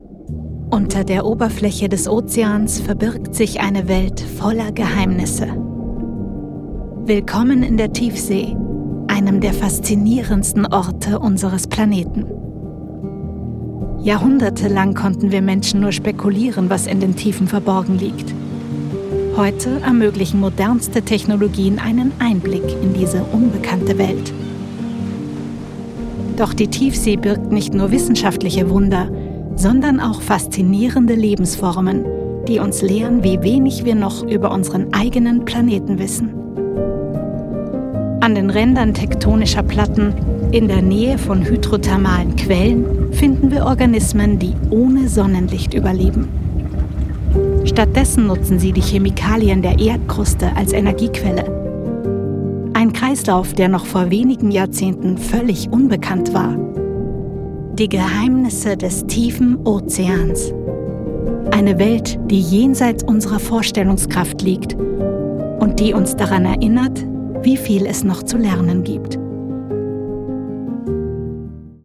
Dokumentation – Die Geheimnisse des tiefen Ozeans
mitteltief ⋅ frisch ⋅ facettenreich